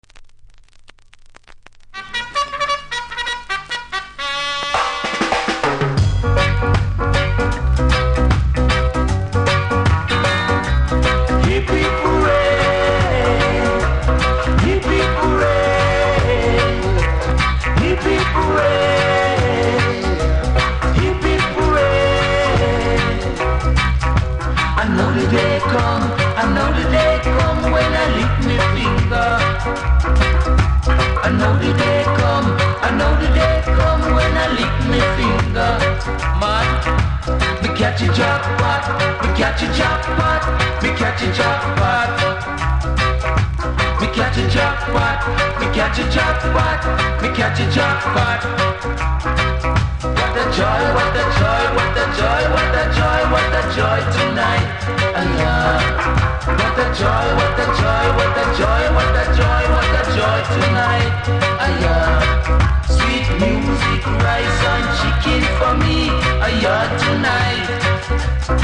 盤の見た目はキズ多めで良くないですがそこそこ聴けます。